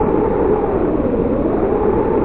1 channel
AMB_WIND.mp3